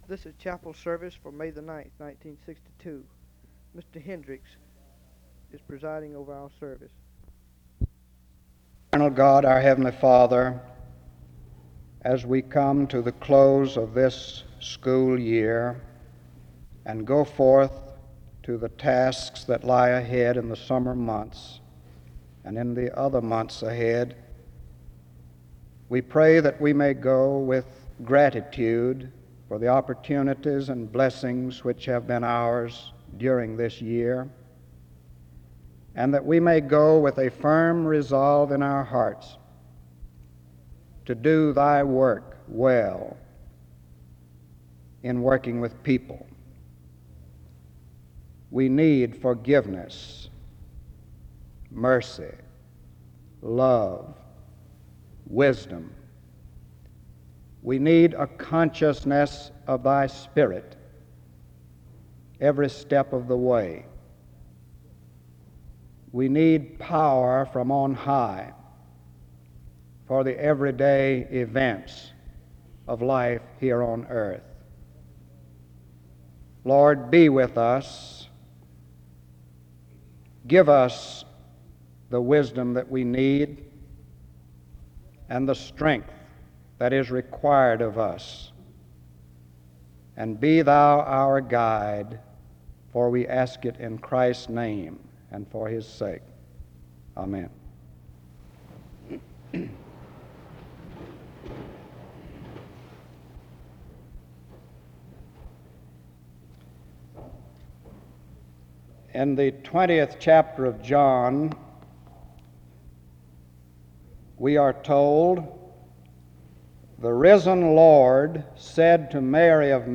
He encourages the student body to share the news of the risen savior as they head out for the summer. This is the last chapel service for the school year.
There is a closing prayer from 11:24-12:18.